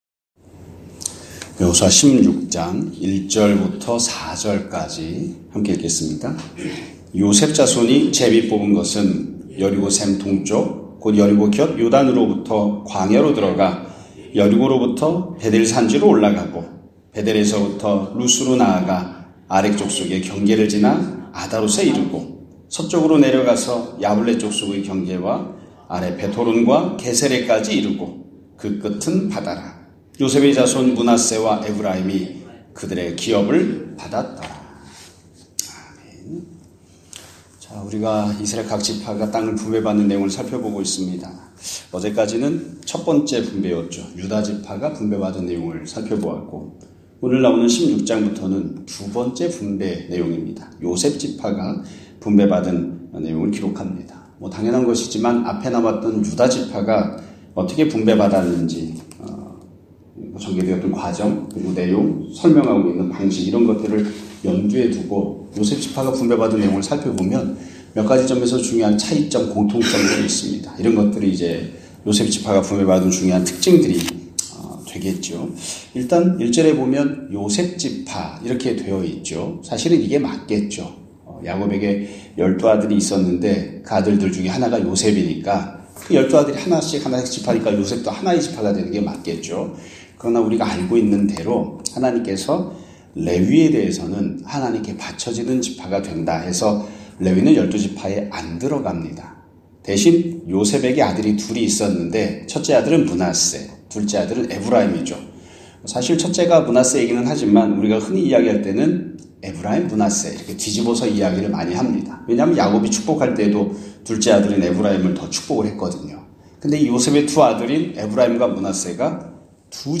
2024년 12월 11일(수요일) <아침예배> 설교입니다.